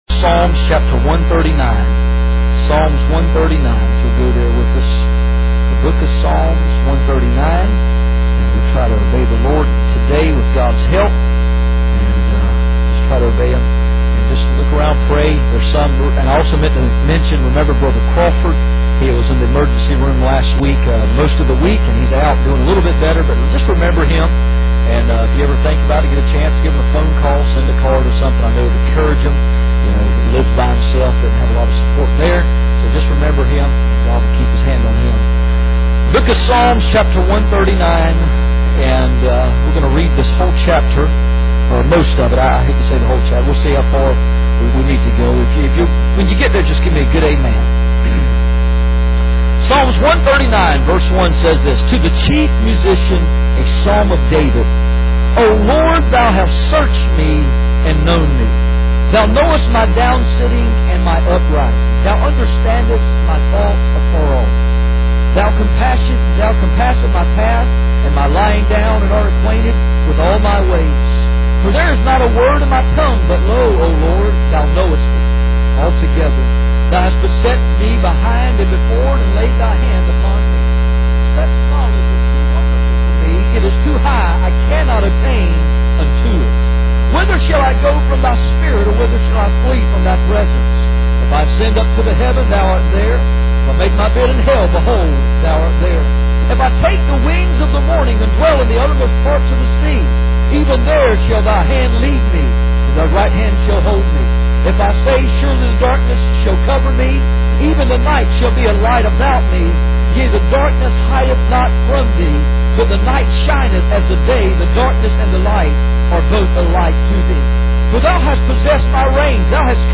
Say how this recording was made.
Passage: Psalm 139:1-24 Service Type: Sunday Morning